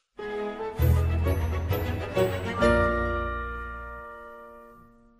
Download Free Sims Sound Effects